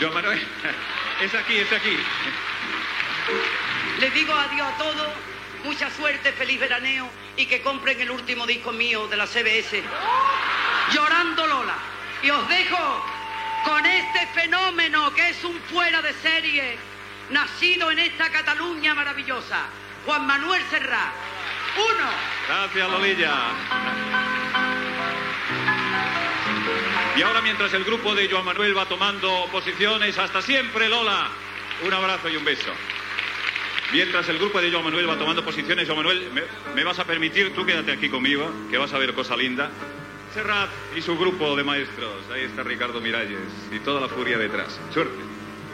Comiat de l'actuació de la cantant Lola Flores al Festival de Radio de la Canción, de Roda de Berà
Entreteniment